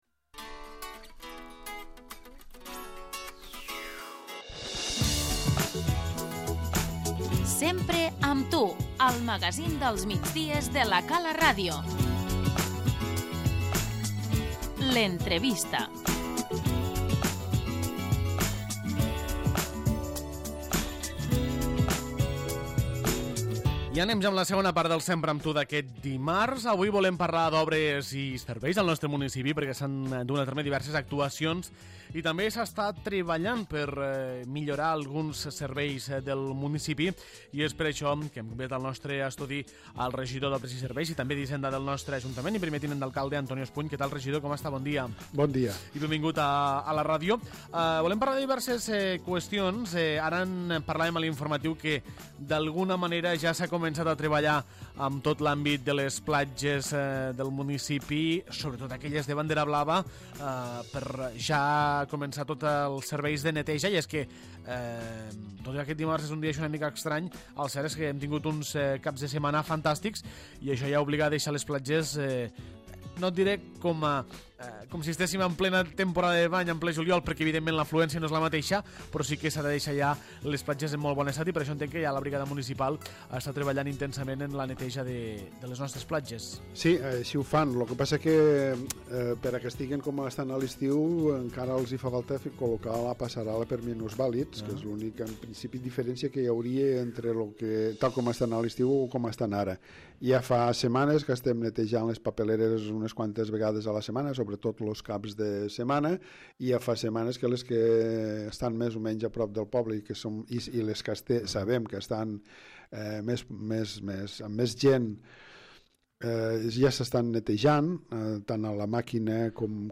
L'entrevista - Antonio Espuny, regidor d'Obres i Serveis